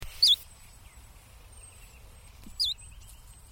Sweeoo Chiffchaffs in Finland in 2011